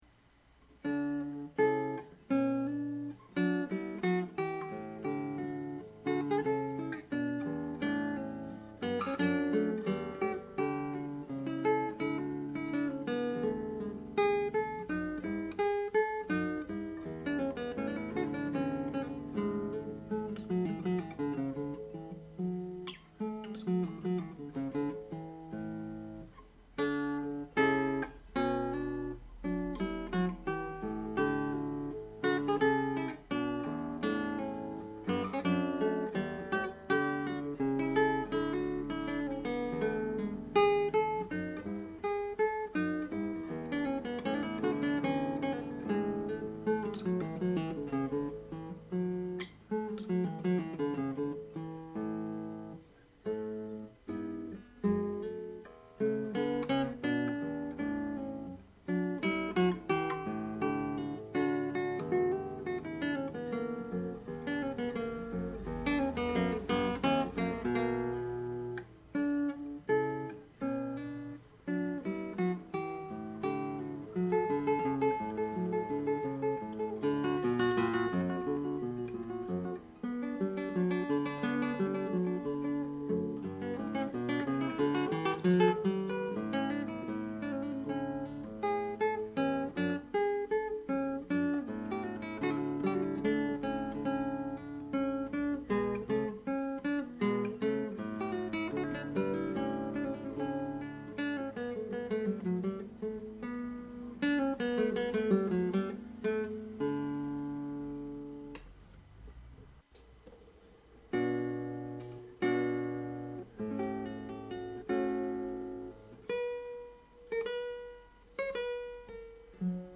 Come sempre....registrazione casalinga...